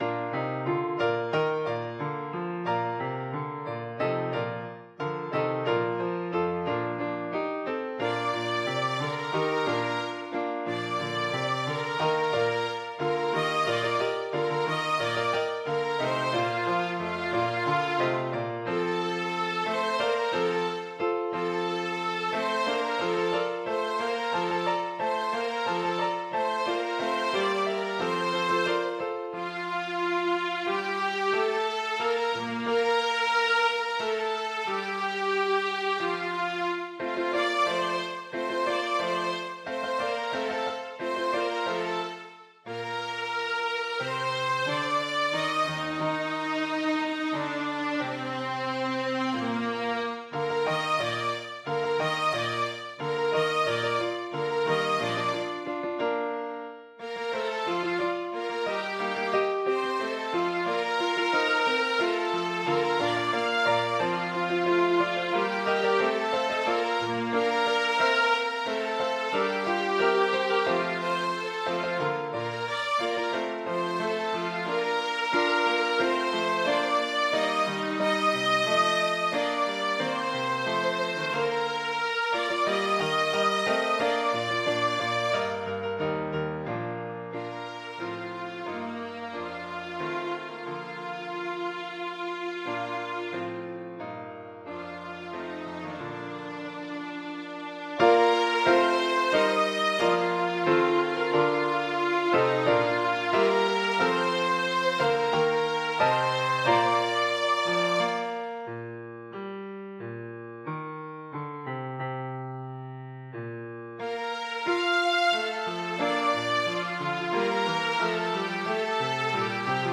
Hợp Xướng Ha-lê-lu-gia, Chúa Là Vua (cho các bè giọng nhất, nhì, ba và tư)